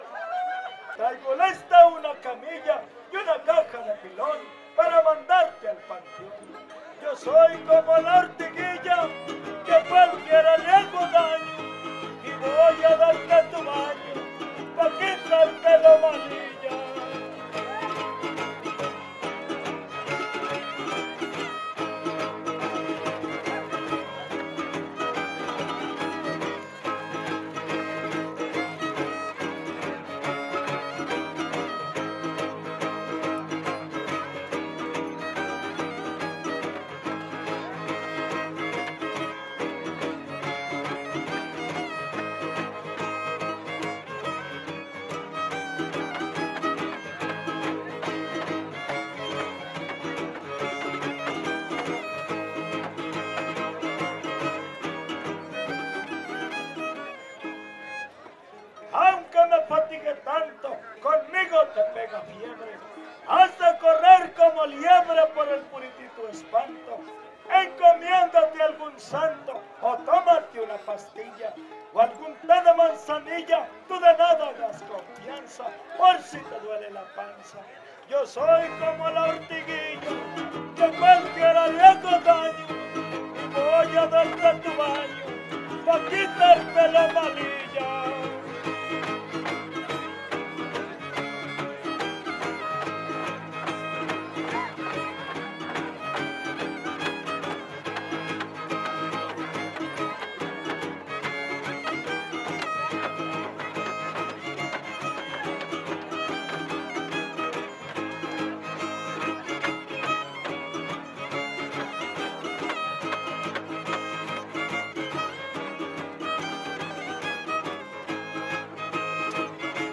Décima Huapango arribeño
guitarra huapanguera
No identificado (violín primero)
Violín Vihuela Guitarra
Topada ejidal: Cárdenas, San Luis Potosí